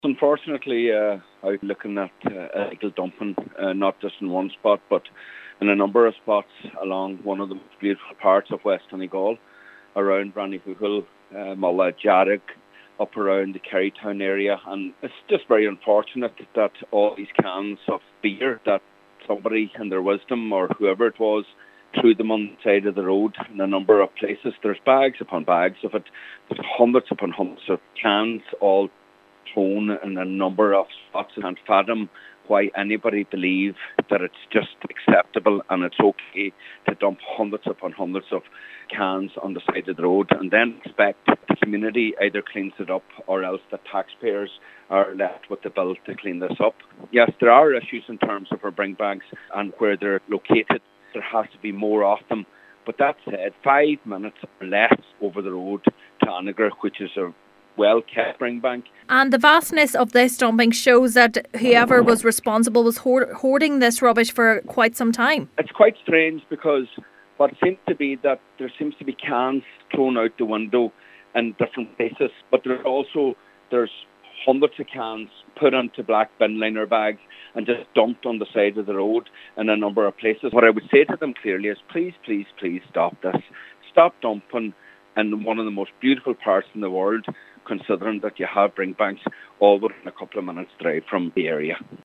Councillor Micheal Cholm MacGiolla Easbuig is appealing to those responsible to desist: